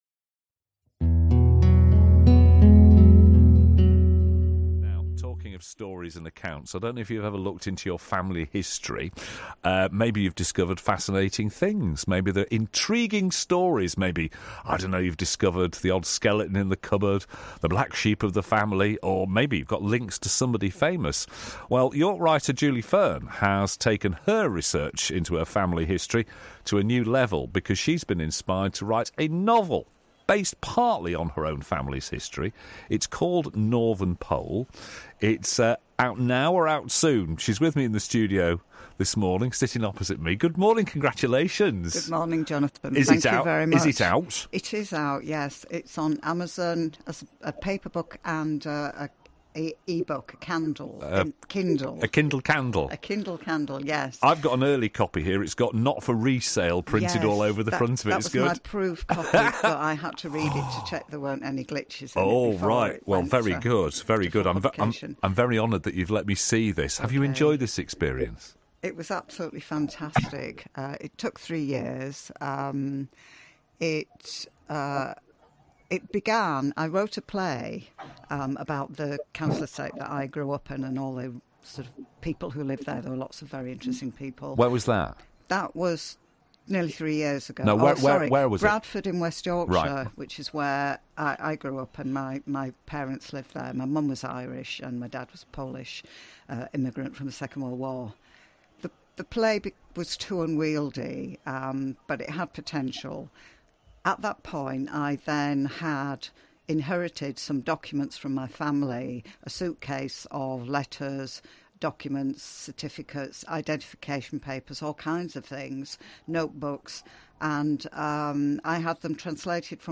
BBC Radio York Interview